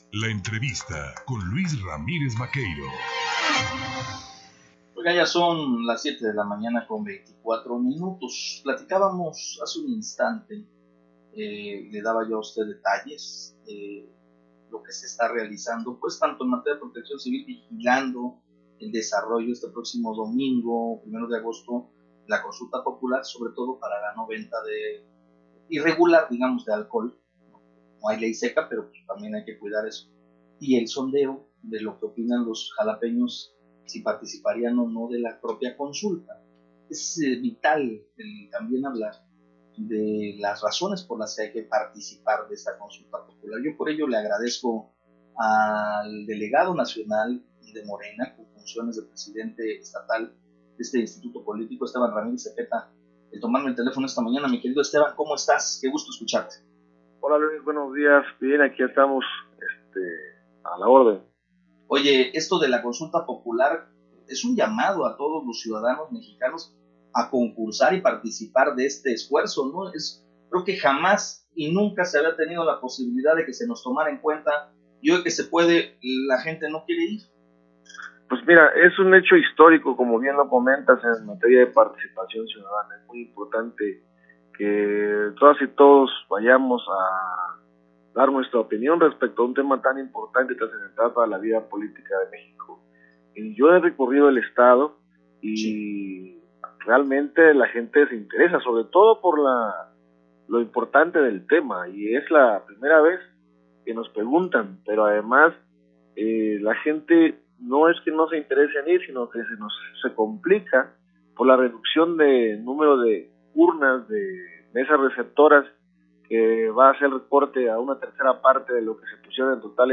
Redacción/Xalapa.- En entrevista para En Contacto